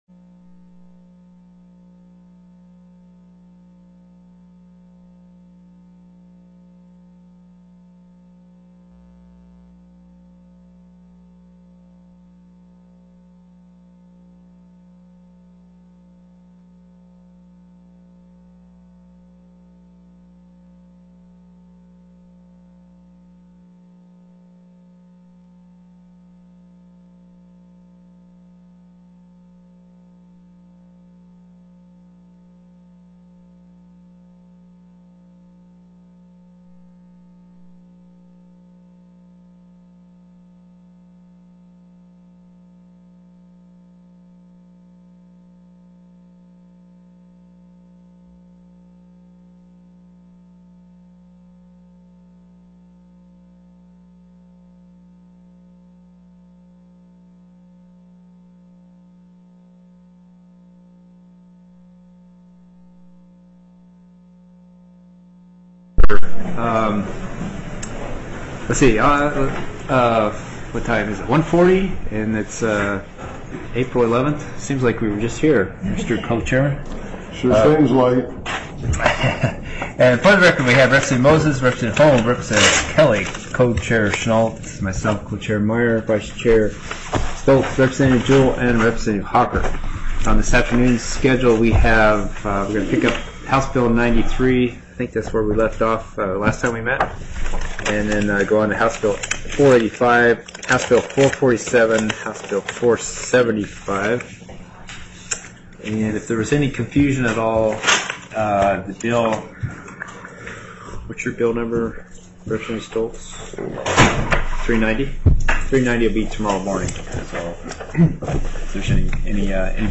TELECONFERENCED